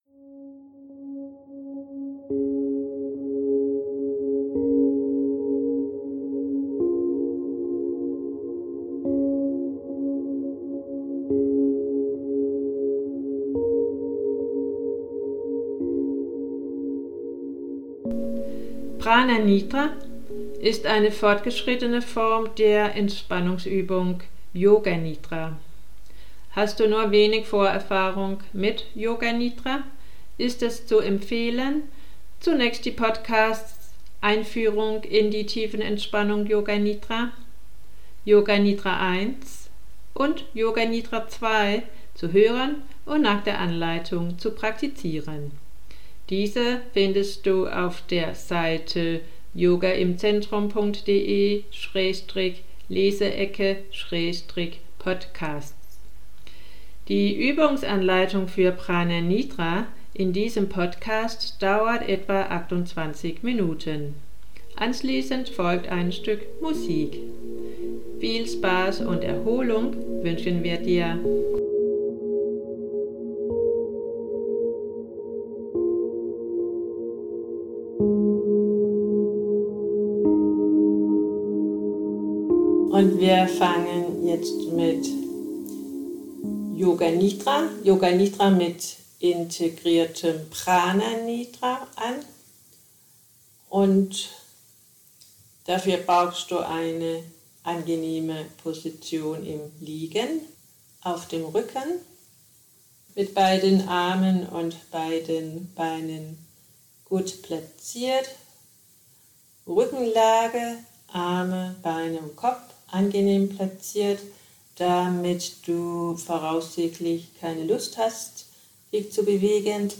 PRANA-NIDRA-Y.mp3